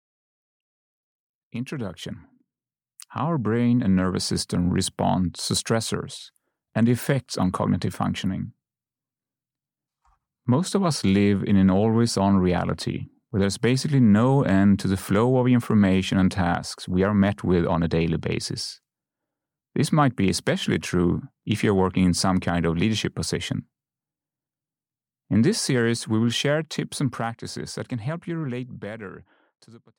Audio knihaSustainable High Performance - Introduction (EN)
Ukázka z knihy